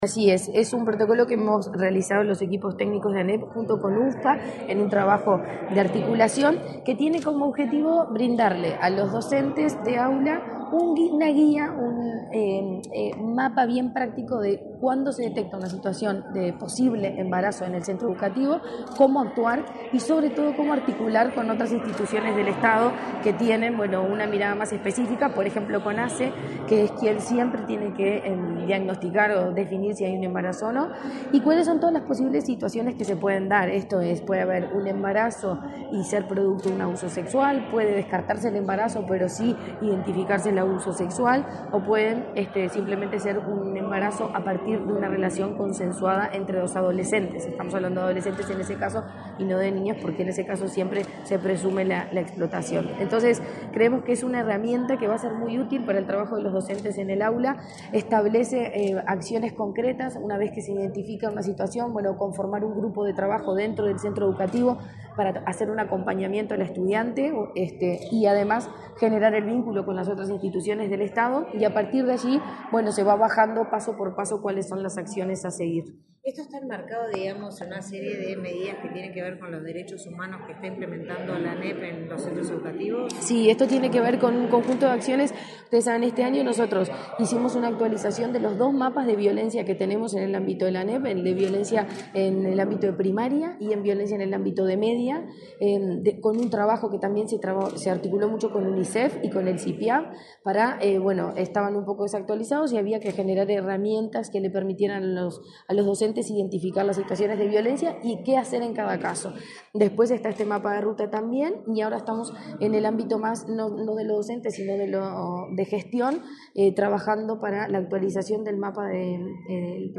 Declaraciones de la presidenta de ANEP, Virginia Cáceres